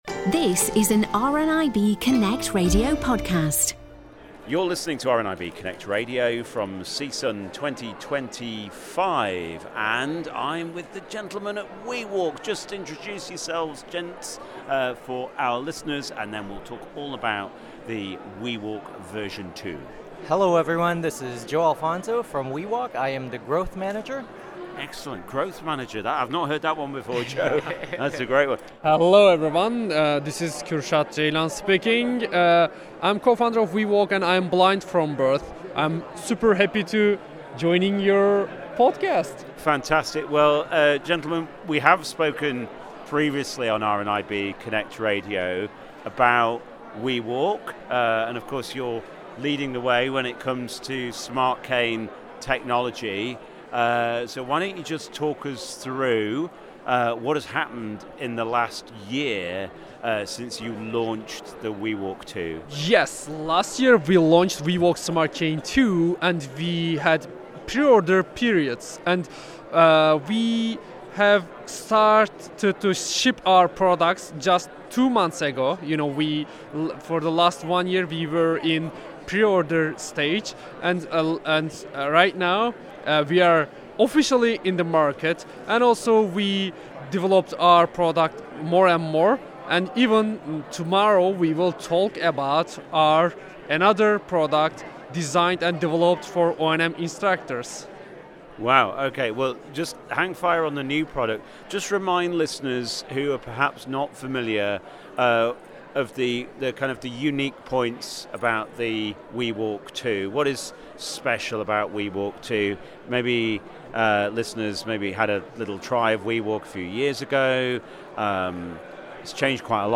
has been on the conference floor this year to speak to some of the delegates